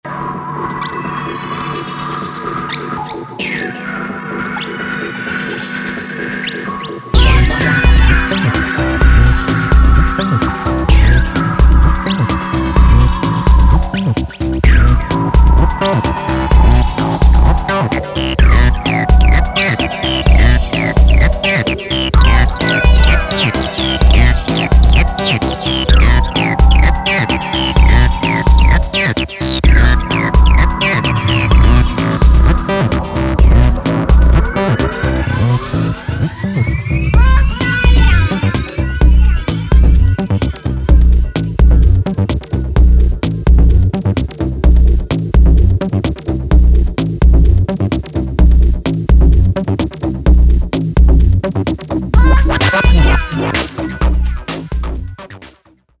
House Techno